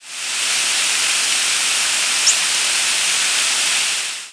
Mourning Warbler nocturnal
presumed Mourning Warbler nocturnal flight calls